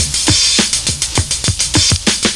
100CYMB03.wav